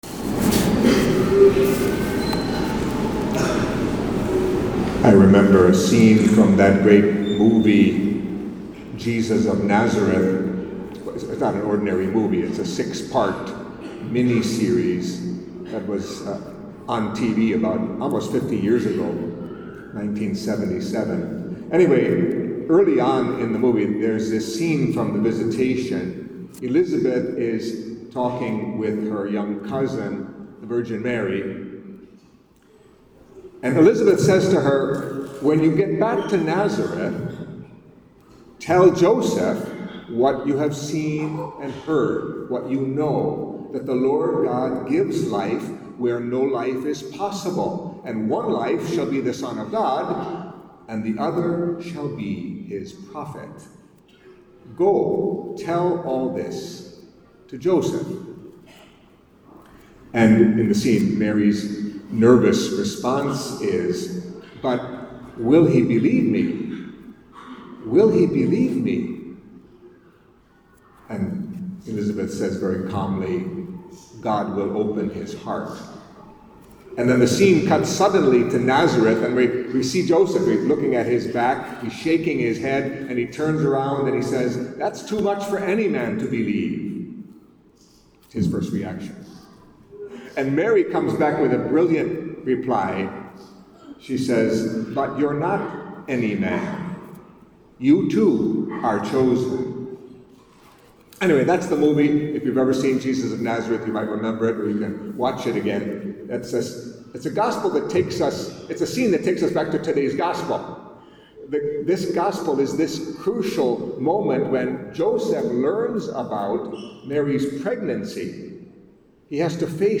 Anawim Homilies